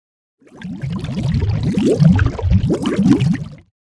suspicious.mp3